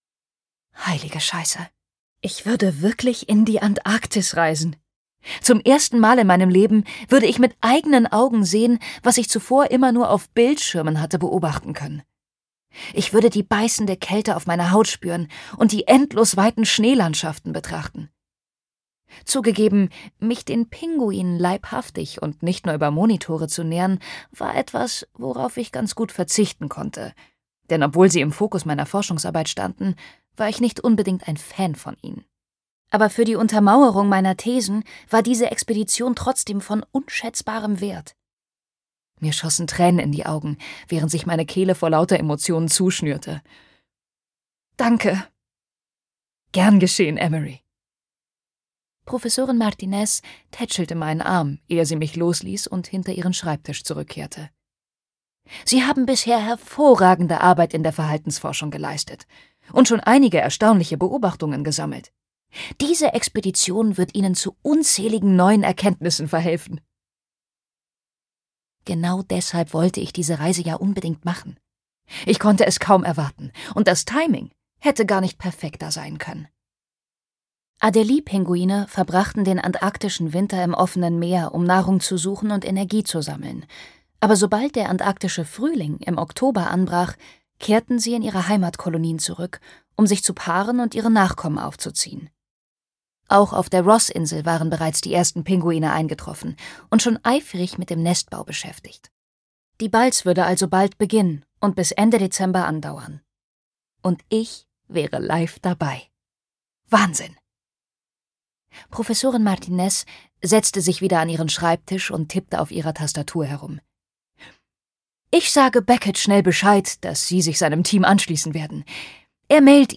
The Penguin Paradox - Greta Milán | argon hörbuch
Gekürzt Autorisierte, d.h. von Autor:innen und / oder Verlagen freigegebene, bearbeitete Fassung.